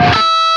LEAD E 4 LP.wav